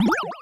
Bubble2.wav